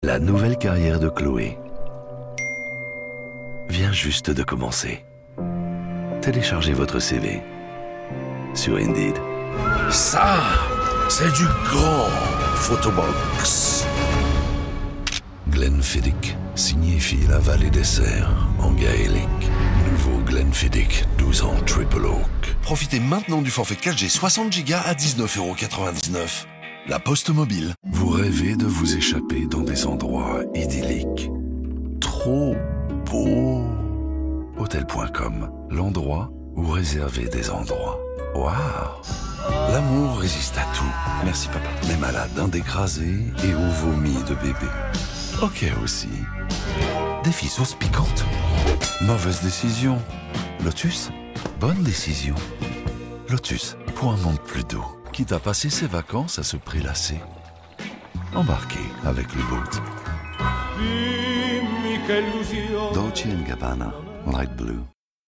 A versatile voice, ranging from warm and reassuring to current and trendy, with the ability to shift into sexy, deep, gritty tones.